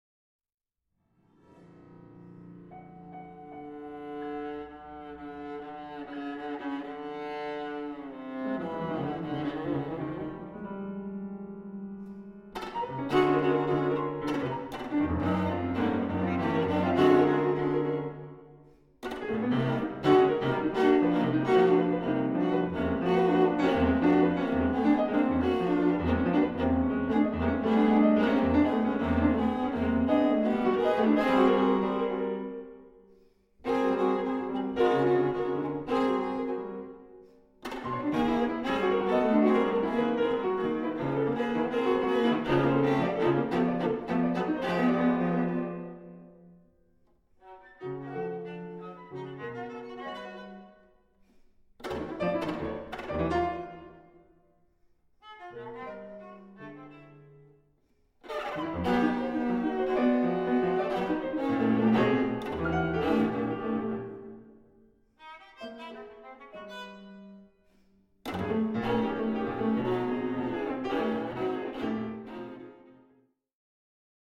Live recording
2019 (Kult-Bau, St. Gallen)